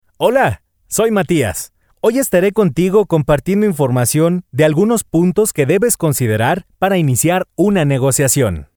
Gracias al tono que manejo (voz media), puedo ofrecer diferentes estilos y tonos para darle calidad a tu proyecto.
Sprechprobe: eLearning (Muttersprache):